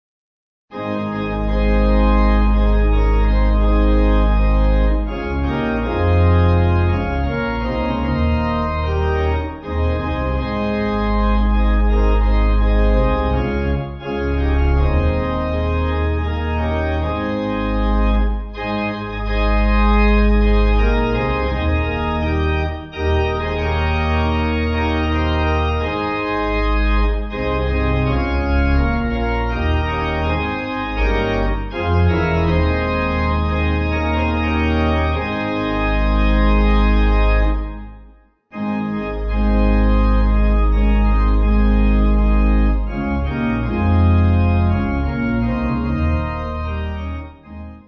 Faster